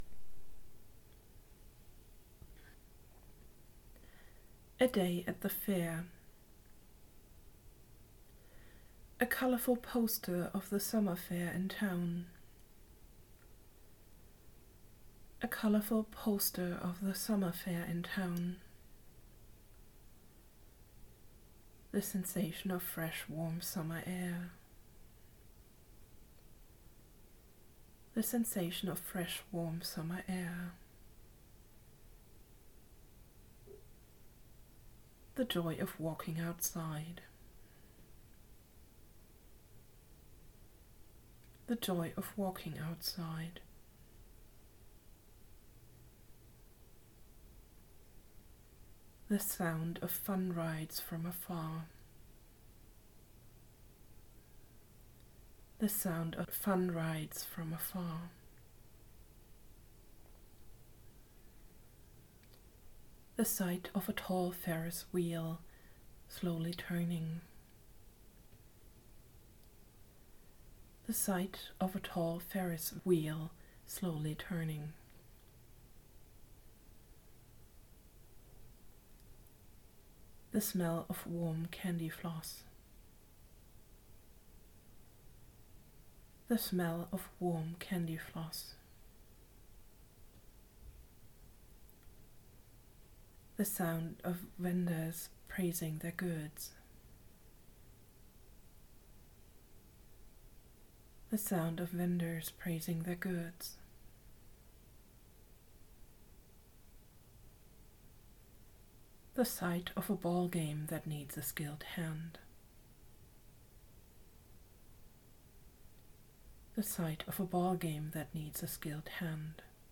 by on in english, guided imagery
In this short-form approach you get instructions to focus on imagining one sensory experience after the other. The instruction is repeated twice and then you are asked to shift to the next picture or sensory experience immediately. It is supposed to be so fast-paced that you have no time to come up with negative ideas or elaborate inner stories.